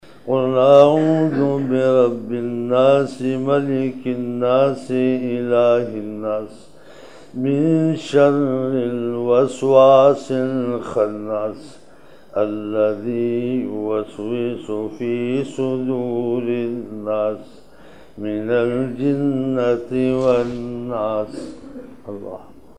Tilawat - Surah Al-Nas
Back Tilawat - Surah Al-Nas 10 November 1999 - Wednesday Fajar (2 Shaban 1420 mehfil) 10 Nov 1999 Quran Recitations